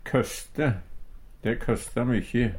køste - Numedalsmål (en-US)